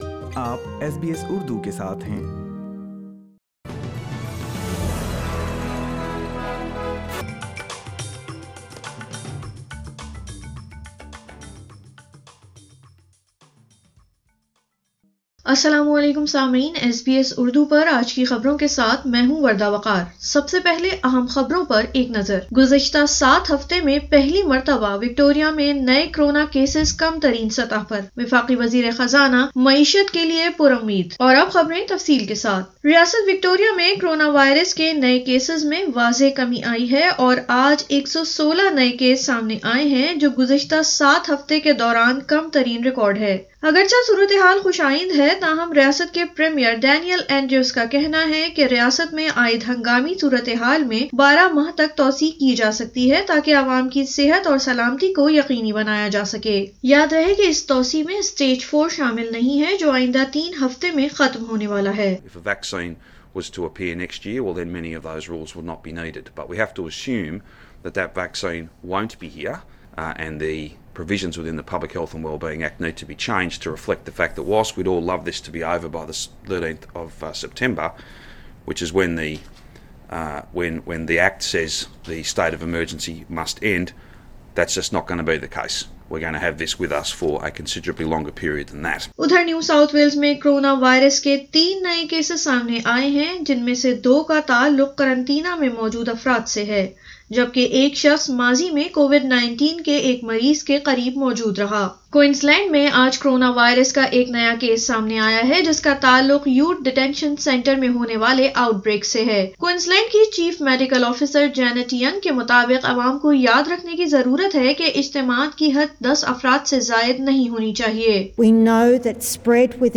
اردو خبریں 24 اگست 2020